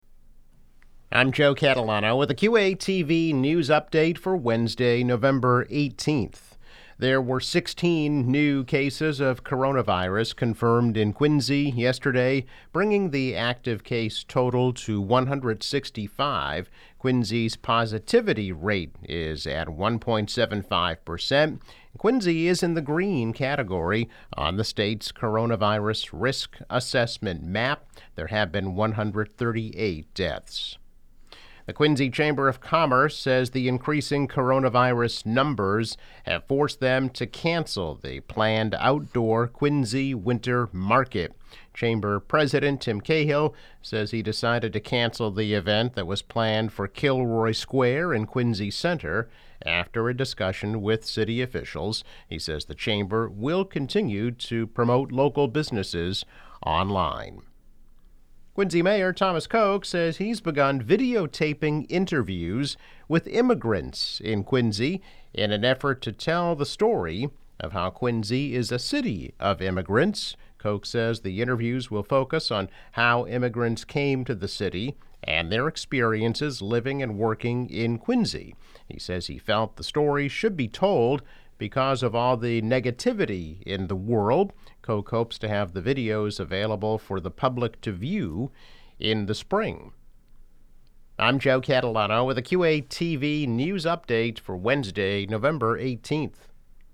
News Update - November 18, 2020